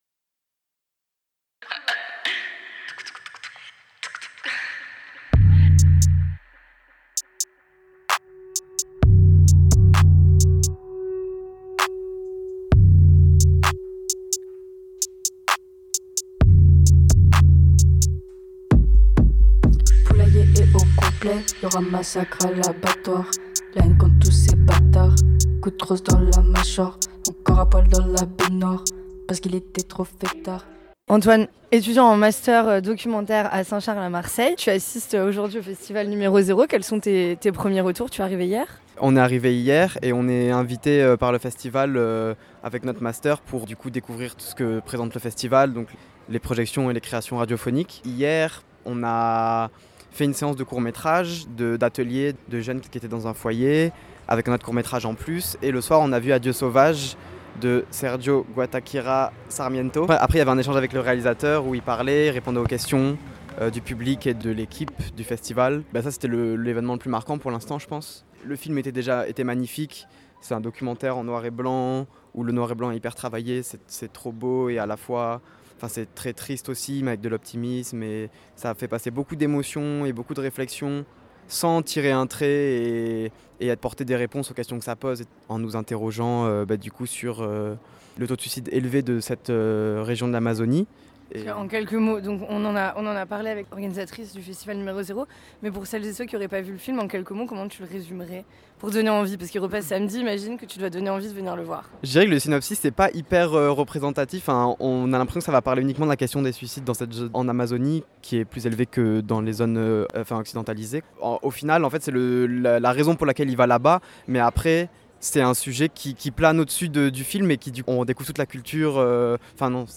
numero zero (9.49 Mo) Le Festival Numéro Zéro organisé par la Miroiterie a animé la ville de Forcalquier du 17 au 21 avril avec une programmation ciné, docu, sonore diversifiée. Quelques retours de festivalier/e/s & partenaires.